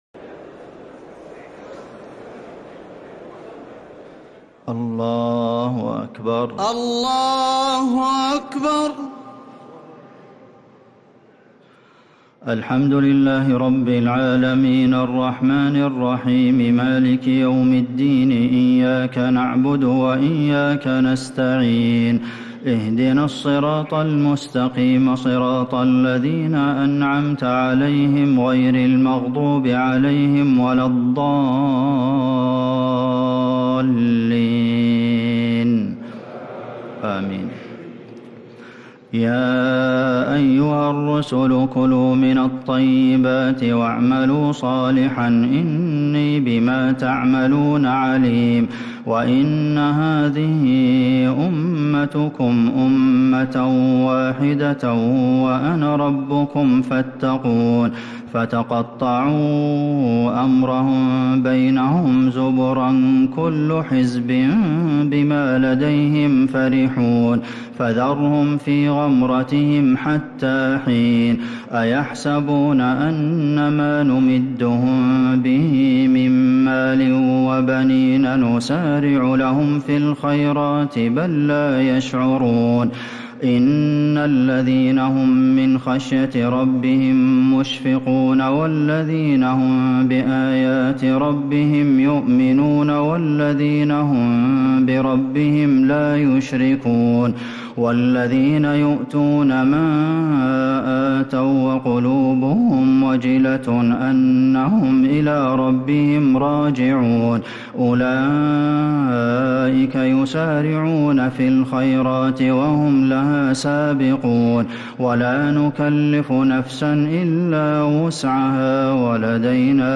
تراويح ليلة 22 رمضان 1446هـ من سورتي المؤمنون (51-118) و النور (1-20) | Taraweeh 22nd night Ramadan 1446H Surah Al-Muminoon and An-Noor > تراويح الحرم النبوي عام 1446 🕌 > التراويح - تلاوات الحرمين